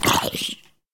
Sound / Minecraft / mob / zombie / hurt1.ogg
hurt1.ogg